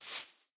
grass5.ogg